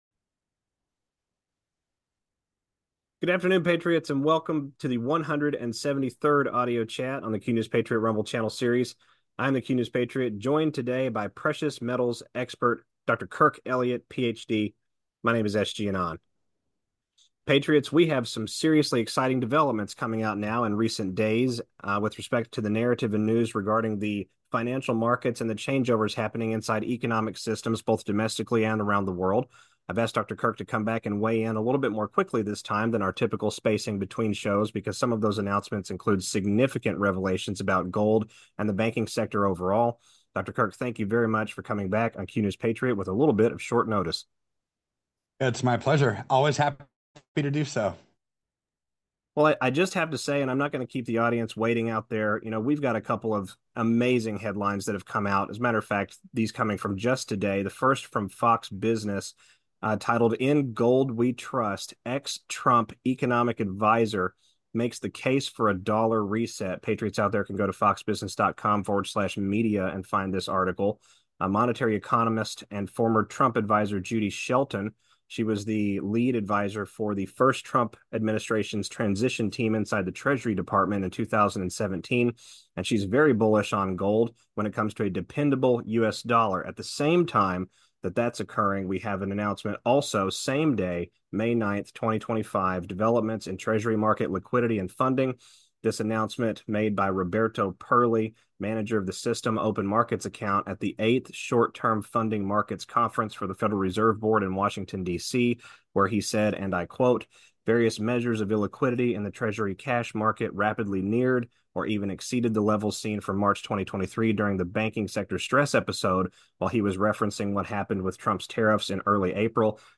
AUDIO CHAT 173